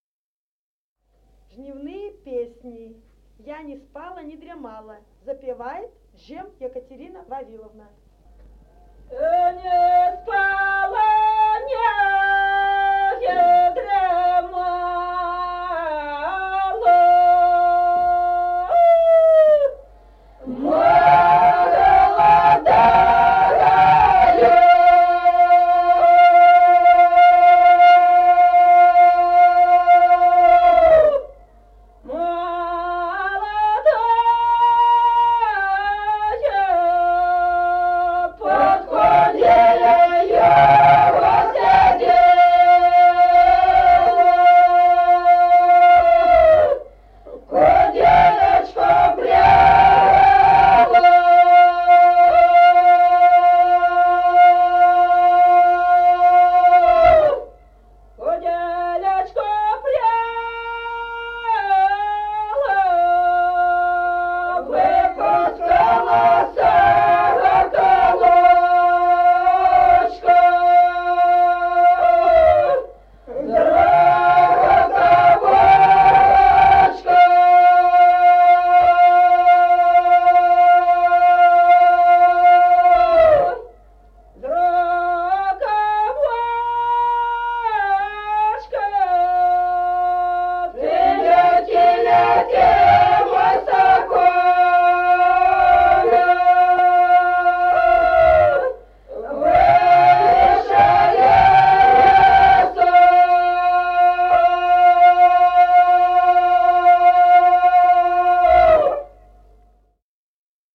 Песни села Остроглядово. А не спала, не дремала (жнивная).